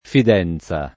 [ fid $ n Z a ]